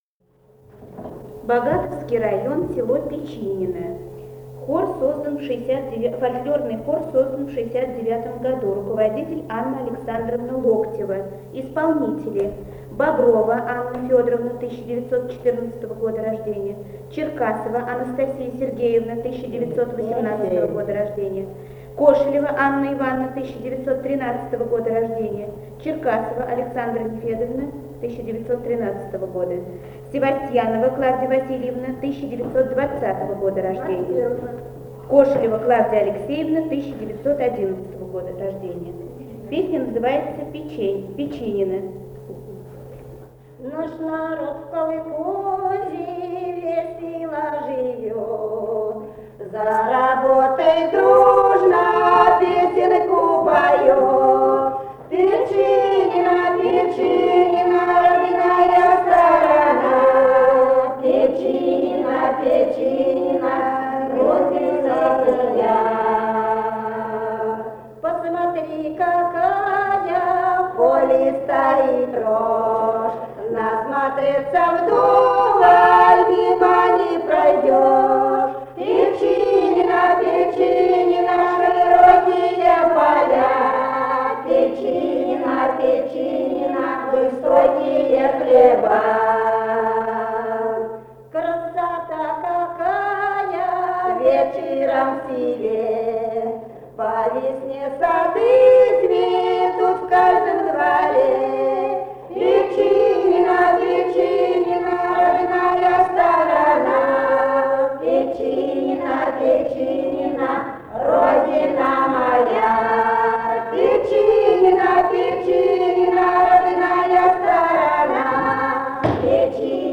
Этномузыкологические исследования и полевые материалы
Самарская область, с. Печинено Богатовского района, 1972 г. И1318-23